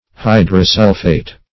Hydrosulphate \Hy`dro*sul"phate\, n.
hydrosulphate.mp3